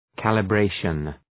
Προφορά
{,kælə’breıʃən}